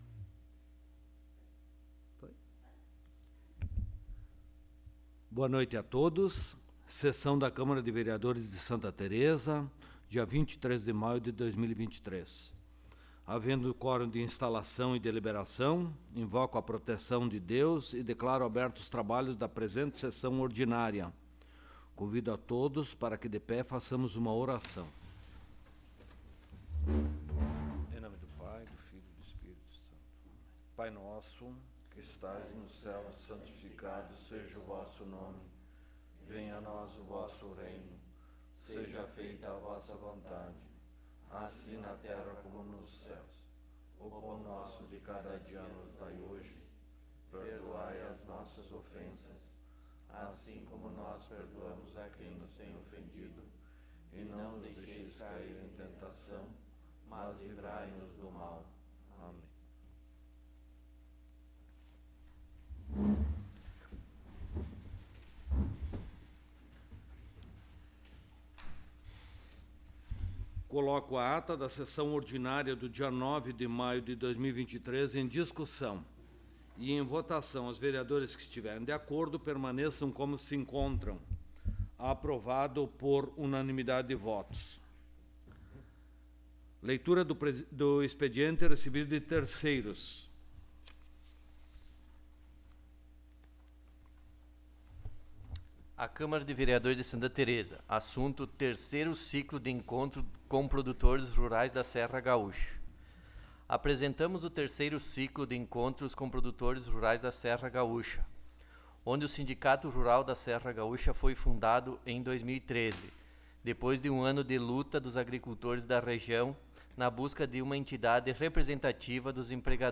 Local: Plenário Pedro Parenti
Áudio da Sessão
Vereadores presentes